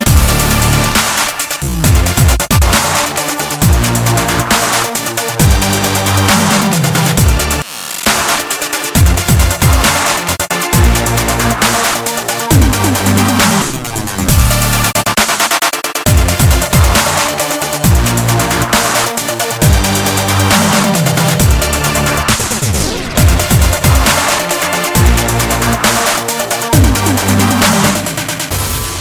качает